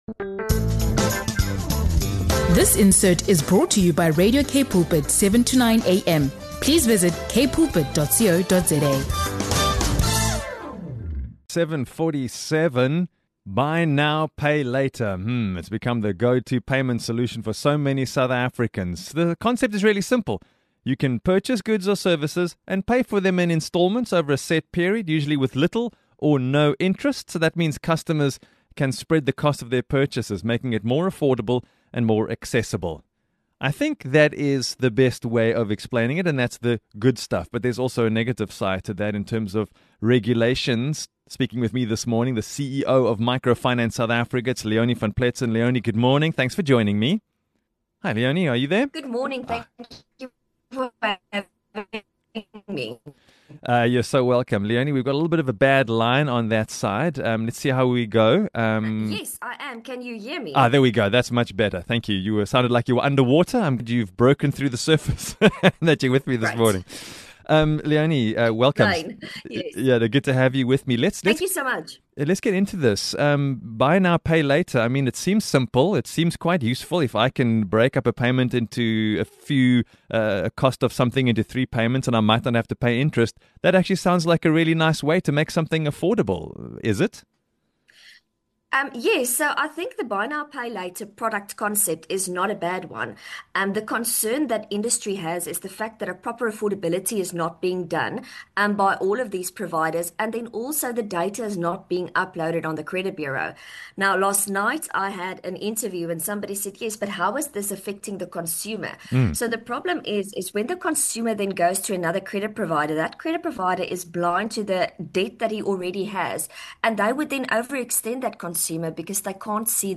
GET UP & GO BREAKFAST - INTERVIEW SPECIALS